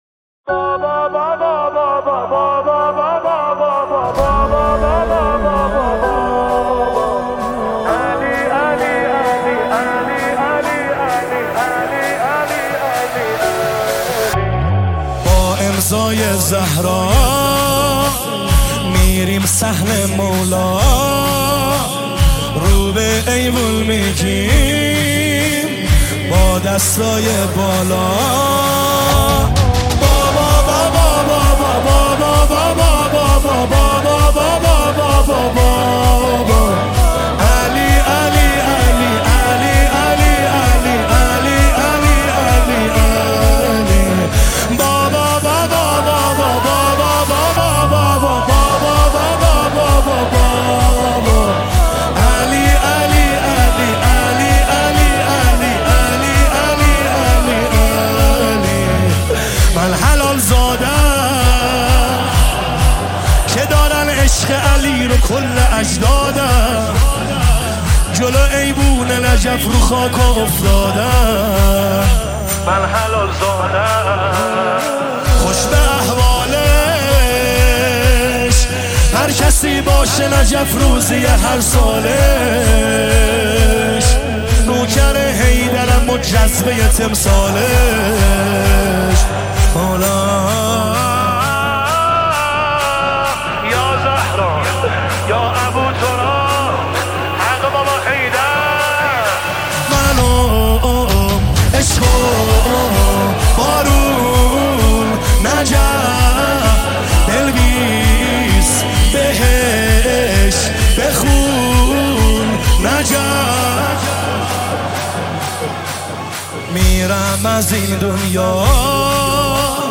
دانلود نماهنگ دلنشین